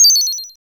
SI2 BLEEP.wav